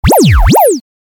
دانلود صدای موس 9 از ساعد نیوز با لینک مستقیم و کیفیت بالا
جلوه های صوتی
برچسب: دانلود آهنگ های افکت صوتی اشیاء دانلود آلبوم صدای کلیک موس از افکت صوتی اشیاء